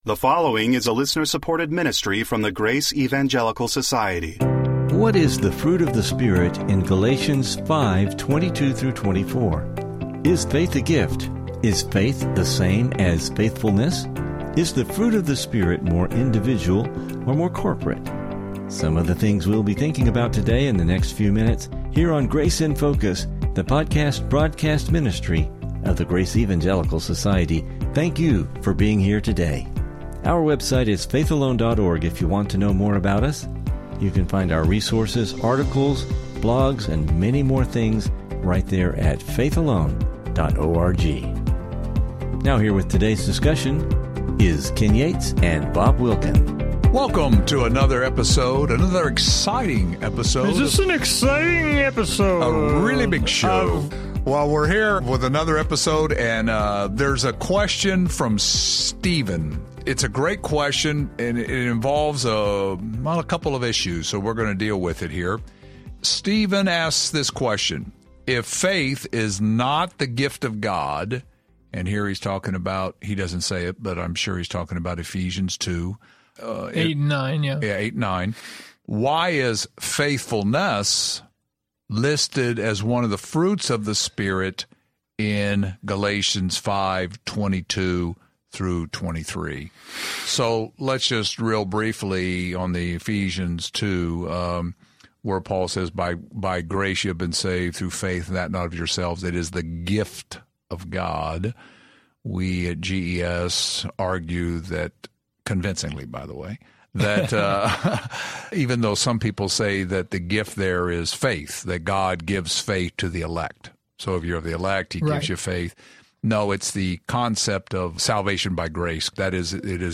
Welcome to Grace in Focus radio/podcast.